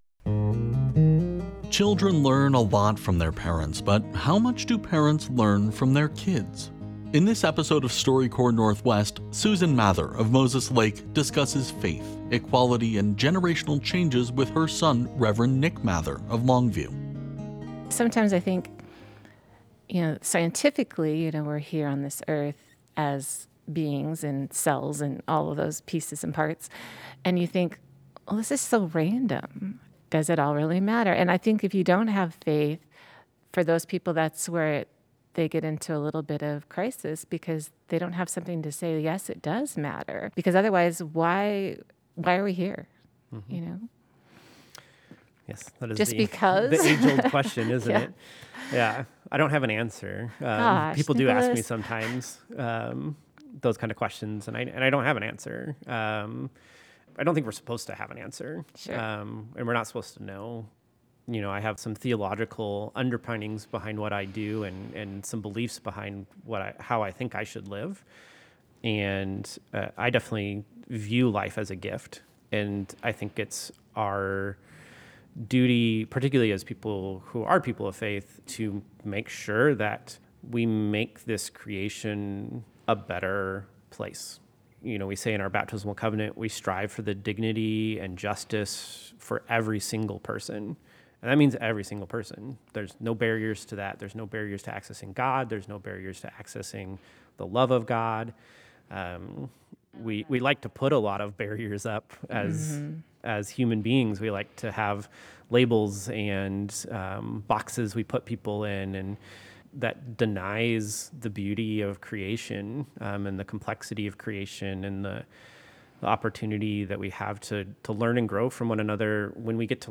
This episode of StoryCorps Northwest was recorded in Moses Lake as part of Northwest Public Broadcasting’s centennial celebration.